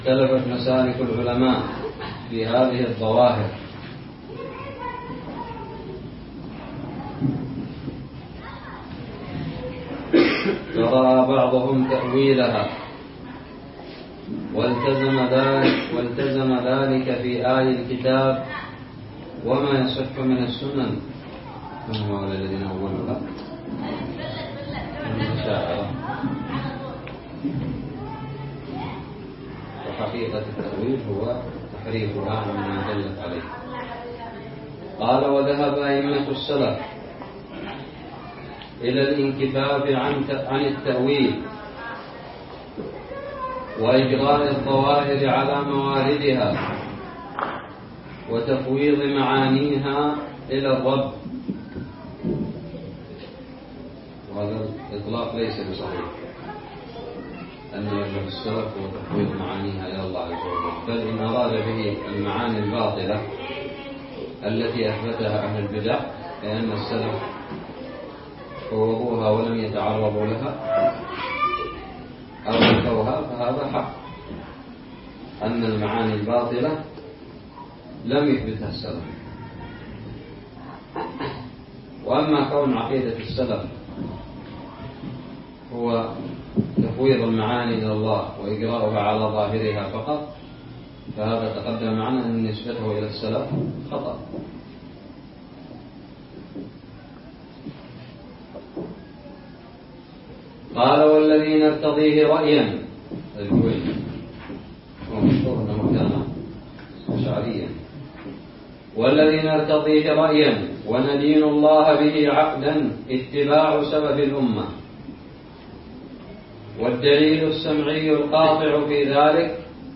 الدرس الثاني والعشرون من شرح متن الحموية
ألقيت بدار الحديث السلفية للعلوم الشرعية بالضالع